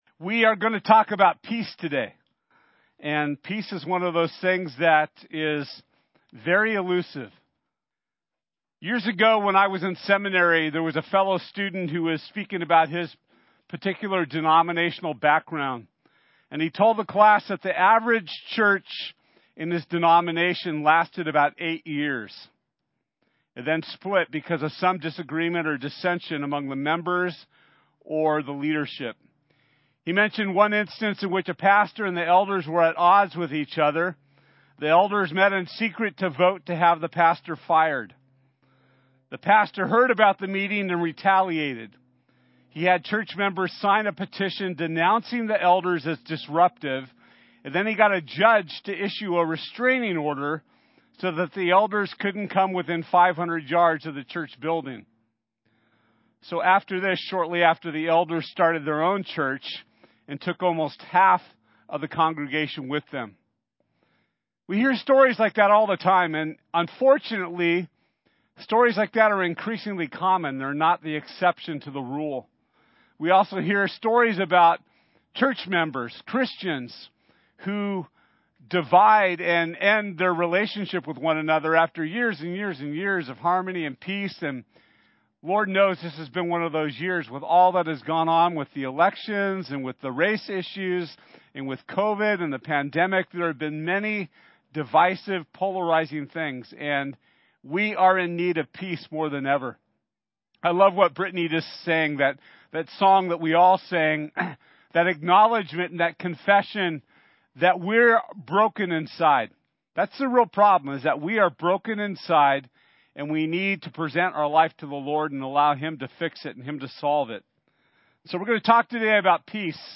One Another Service Type: Sunday This Sunday we’ll be talking about what it means to Unite One Another through the Peace of Christ.